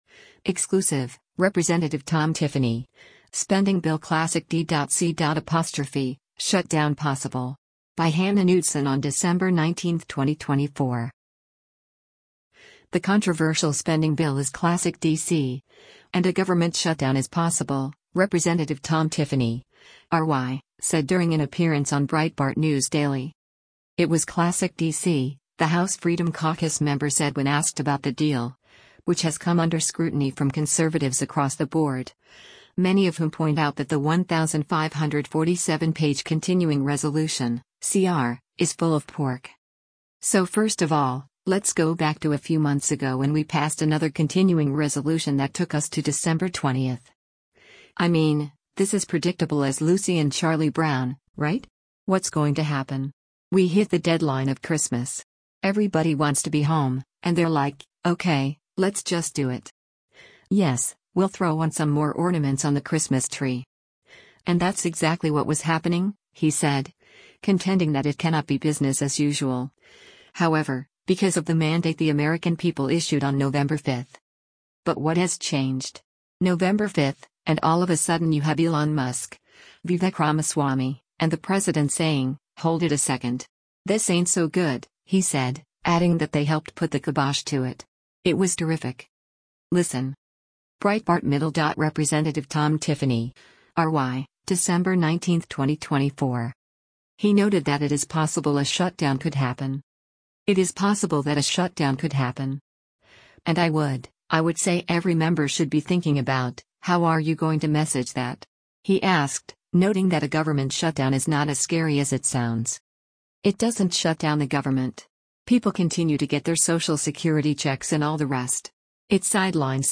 The controversial spending bill is “classic D.C.,” and a government shutdown is possible, Rep. Tom Tiffany (R-WI) said during an appearance on Breitbart News Daily.